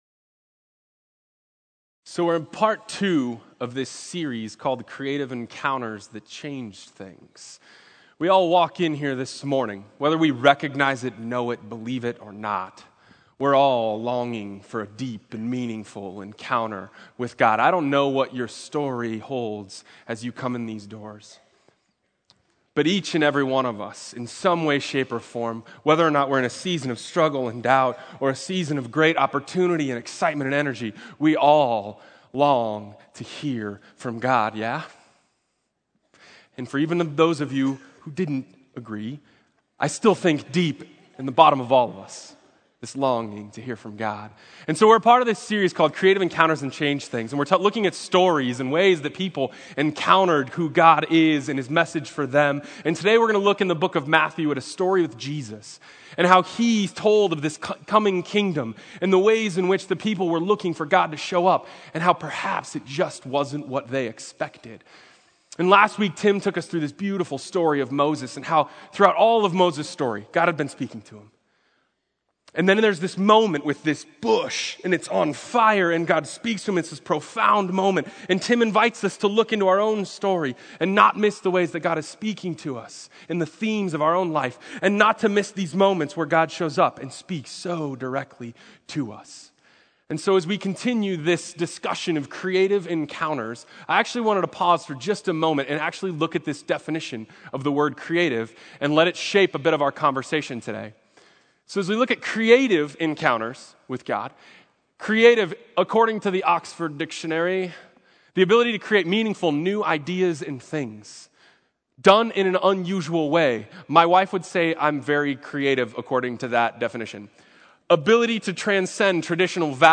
Sermon: It May Not Be What You Expect